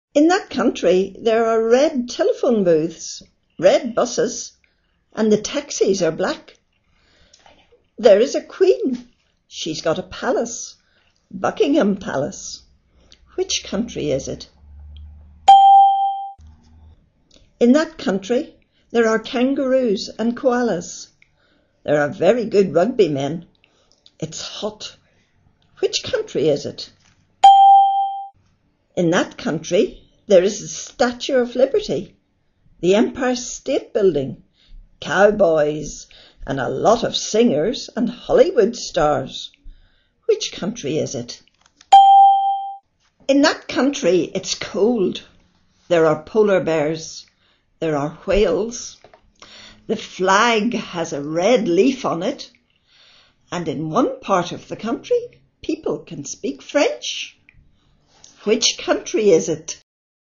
We listened to a person talking about different English-speaking countries in class.